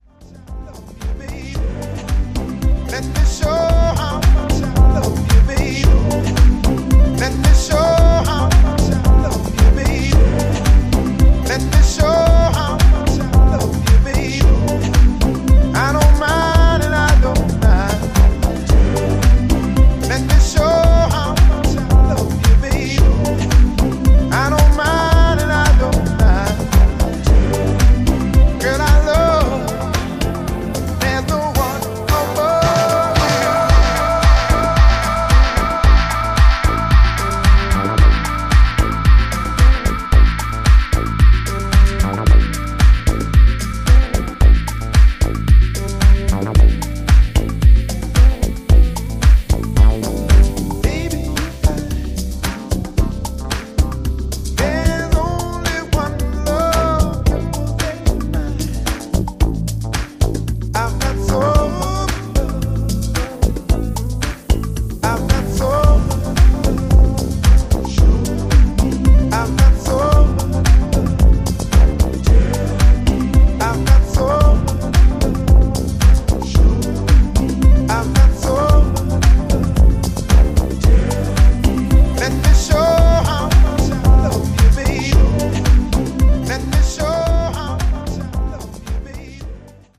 stone cold soul classics remixed for the floor